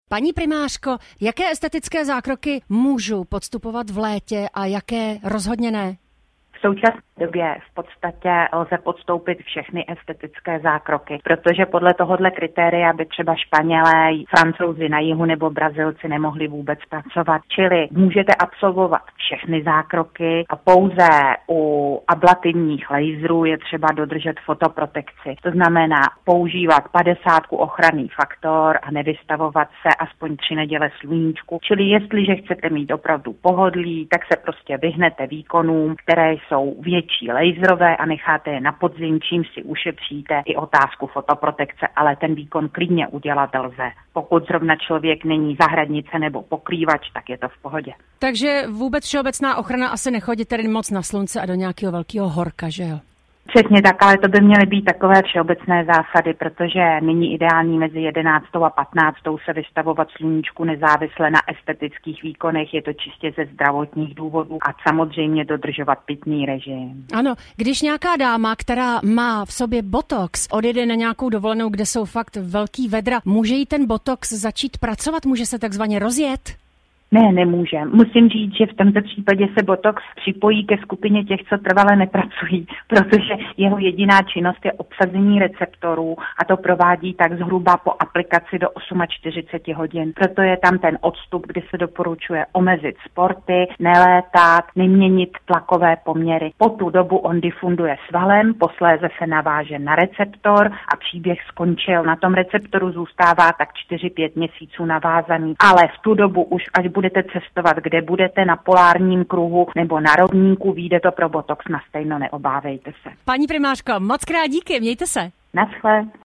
prostřednictvím přímých vstupů do vysílání.